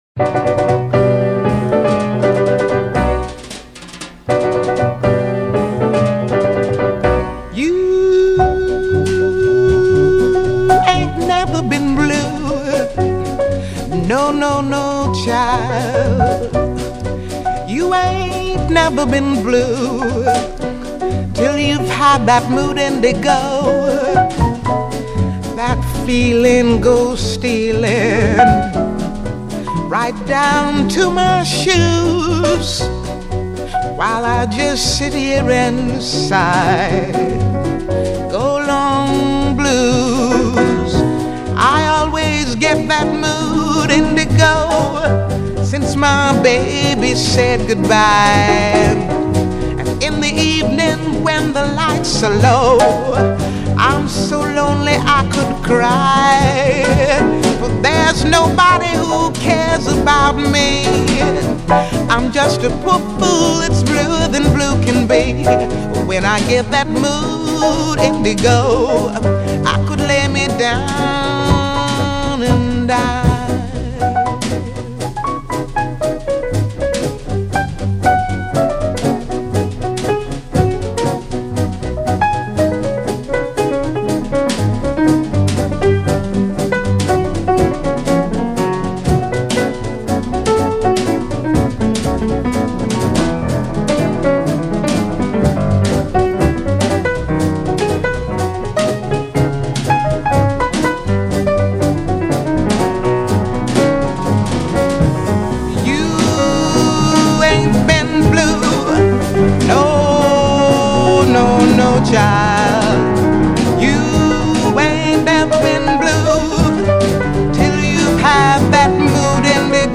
Jazz, Vocal Jazz